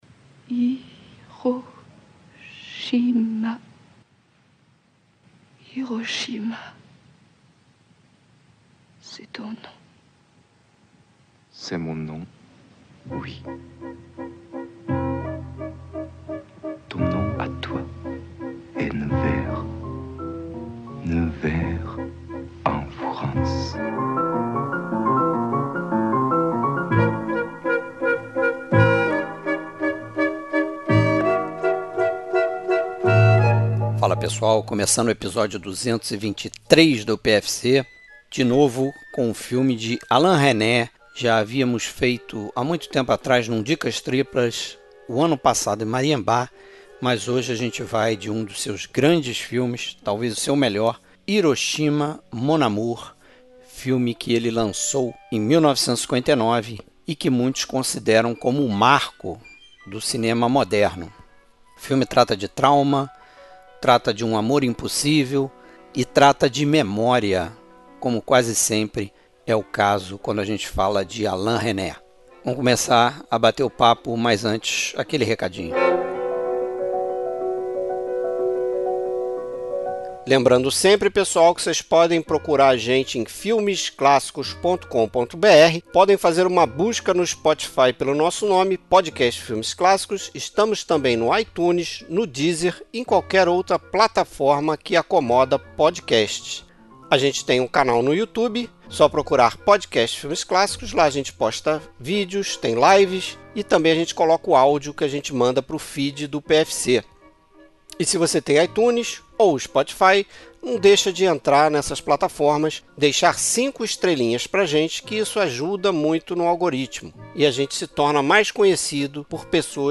Trilha Sonora: trilha sonora que acompanha o filme.